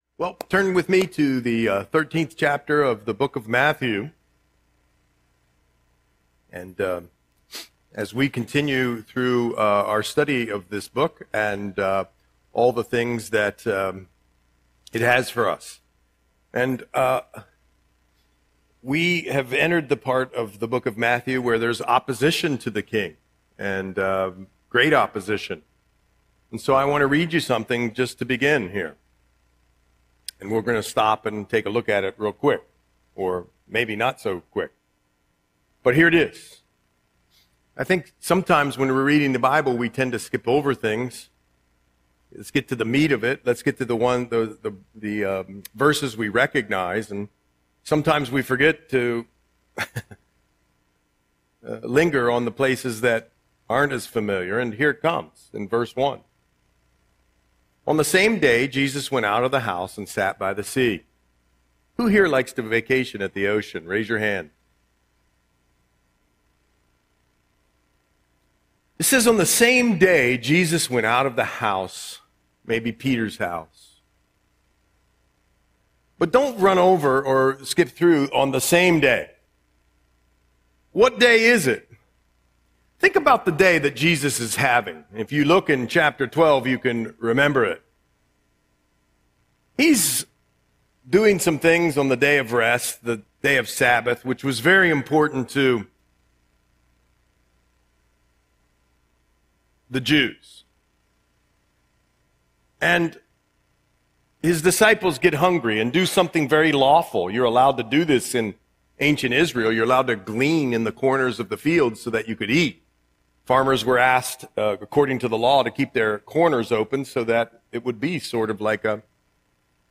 Audio Sermon - March 1, 2026